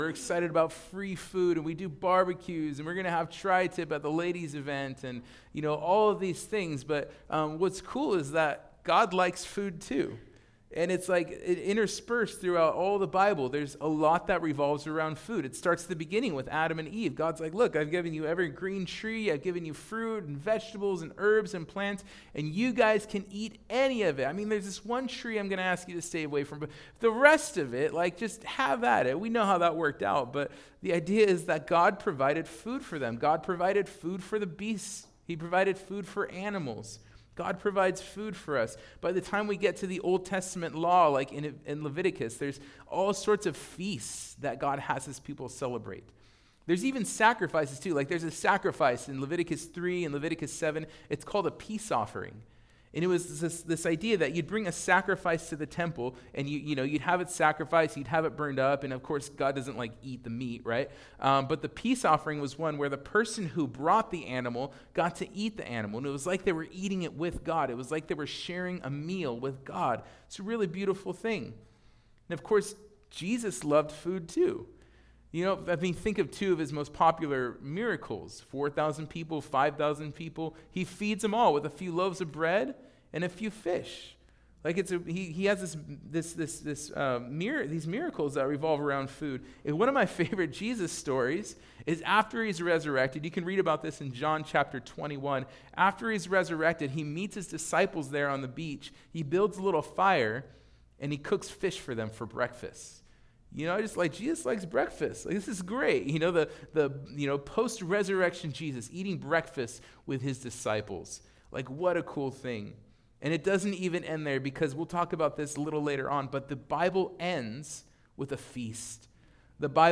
What+Is+The+Lord's+Supper+-+2nd+Service.mp3